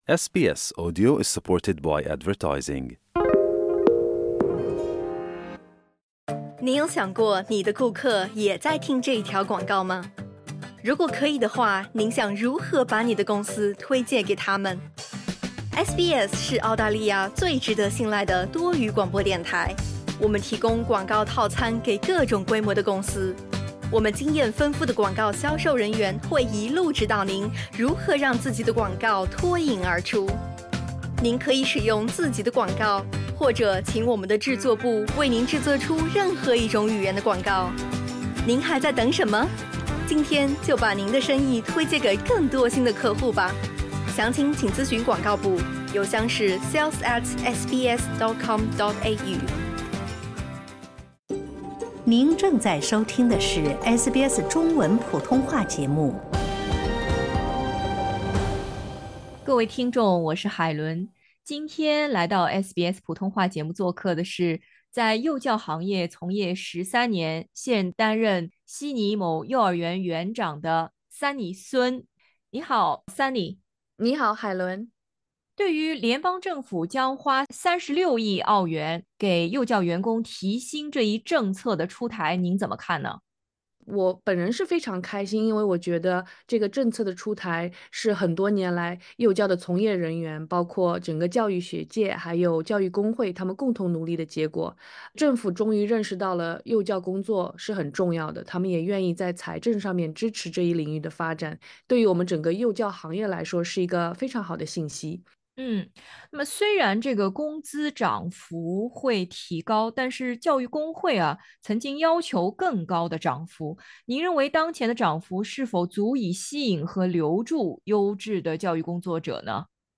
铁路、电车和公交工会在9月末发起了罢工，而历经数年薪资抗争的幼教行业则已达成了15%的提薪协议。请点击音频，收听采访。